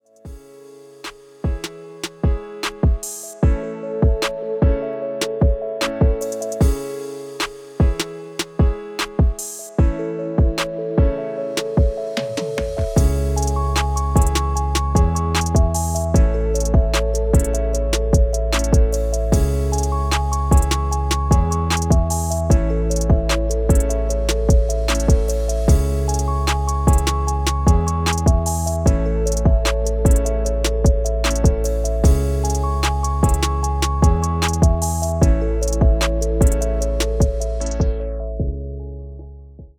• Качество: 320, Stereo
мелодичные
без слов
инструментальные
Chill Trap
beats